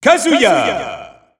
The announcer saying Kazuya's name in French.
Kazuya_French_Announcer_SSBU.wav